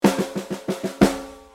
Snare drumroll soundbank 1